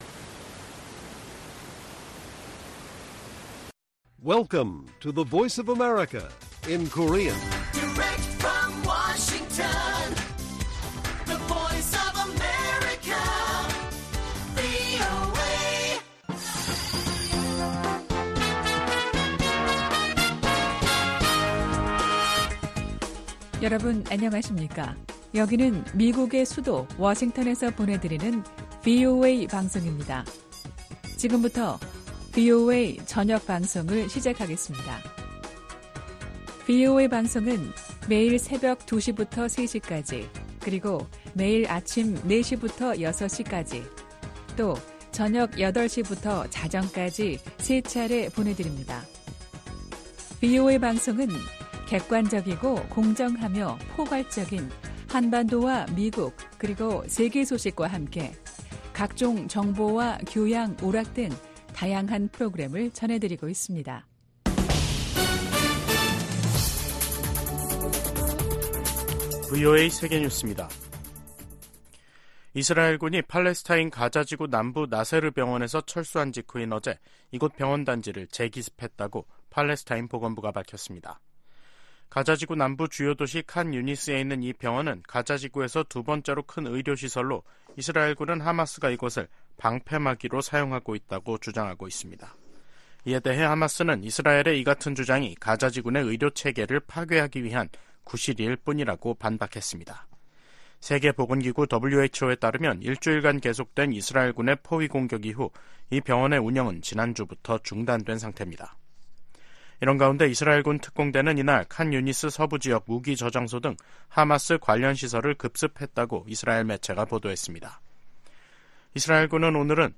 VOA 한국어 간판 뉴스 프로그램 '뉴스 투데이', 2024년 2월 23일 1부 방송입니다. 미국·한국·일본 외교 수장들이 리우데자네이루 주요20개국(G20) 외교장관회의 현장에서 역내 도전 대응 방안을 논의했습니다. 보니 젠킨스 미 국무부 군비통제·국제안보 차관이 북한-러시아의 군사 협력을 심각한 우려이자 심각한 위협으로 규정했습니다. 빅토리아 눌런드 국무부 정무차관은 러시아가 포탄을 얻는 대가로 북한에 어떤 기술을 넘겼을지 누가 알겠느냐며 우려했습니다.